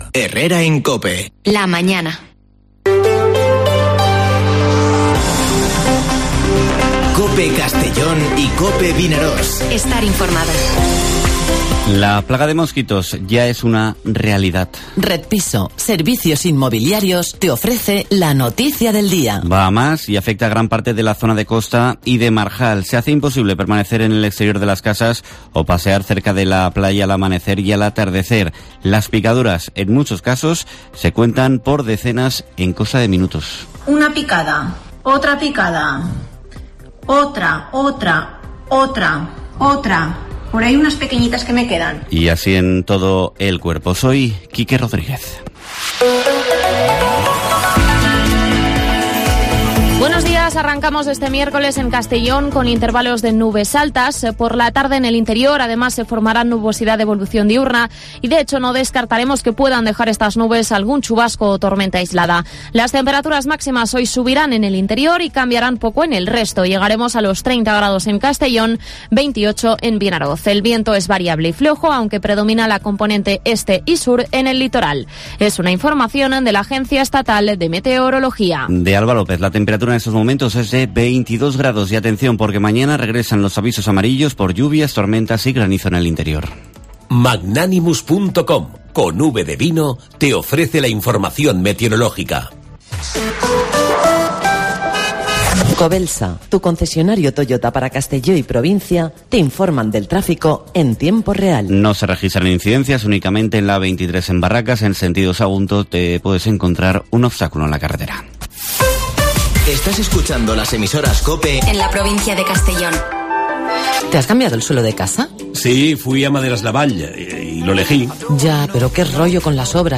Informativo Herrera en COPE en la provincia de Castellón (08/09/2021)